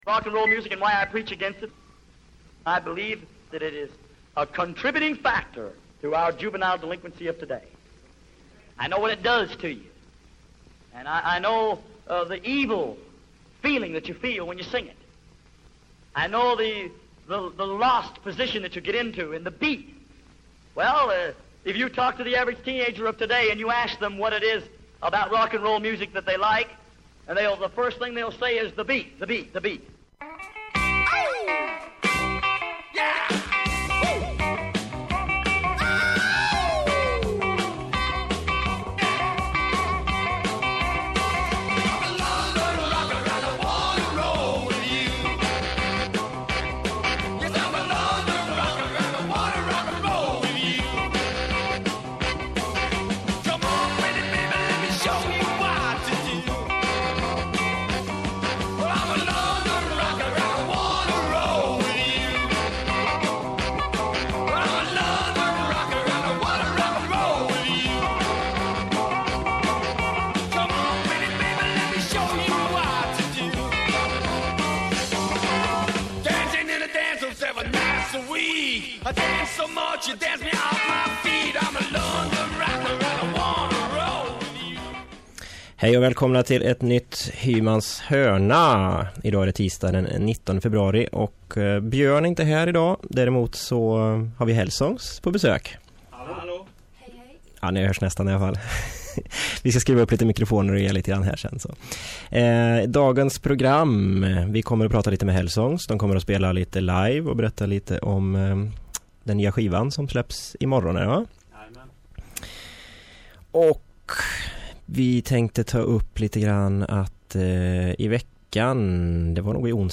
Hymans H�rna handlar om rock n' roll, hela v�gen fr�n country och blues fram till punk och h�rdrock. Det blir sprillans nytt s�v�l som knastrande gammalt, varvat med intervjuer och reportage fr�n G�teborgs musikv�rld.
Det blev lite snack om vad de håller på med, hur allt började och lite om nya skivan som släpps imorgon onsdag. Tre låtar live hann de med att klämma in också.